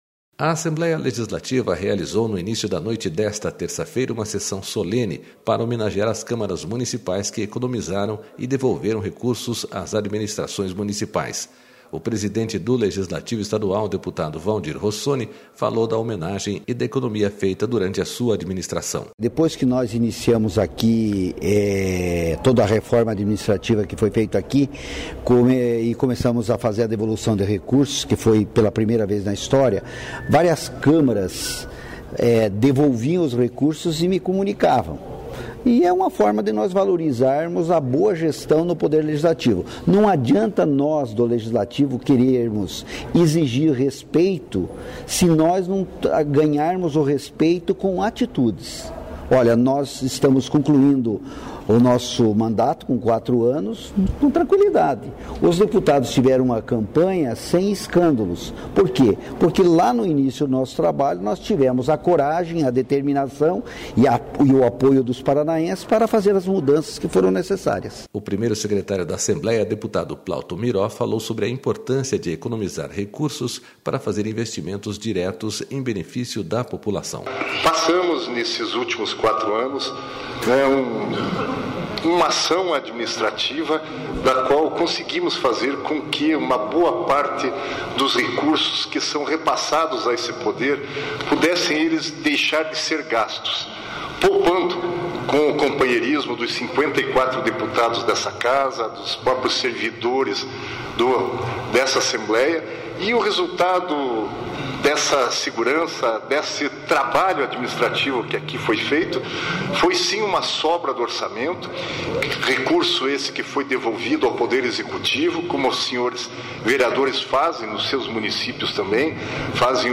A Assembleia Legislativa realizou no início da noite desta terça-feira, uma sessão solene para homenagear as Câmaras Municipais que economizaram e devolveram recursos às administrações municipais.
O presidente do Legislativo estadual, deputado Valdir Rossoni, falou da homenagem e da economia feita durante a sua administração.//
O primeiro-secretário da Assembleia, deputado Plauto Miró, falou sobre a importância de economizar  recursos para fazer investimentos diretos em benefício da população.//